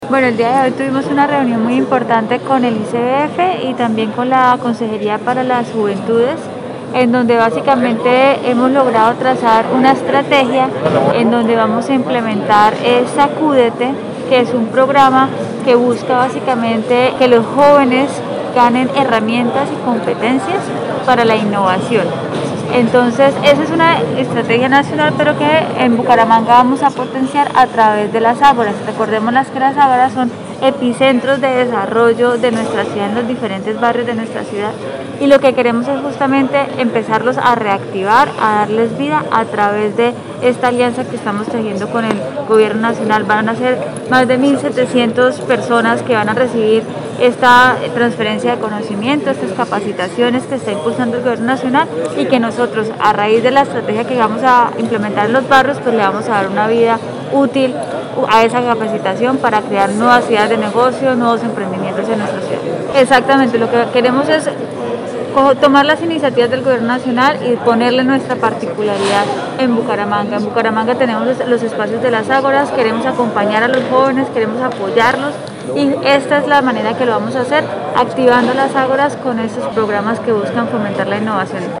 Audio: Natalia Durán, secretaria de Desarrollo Social de Bucaramanga.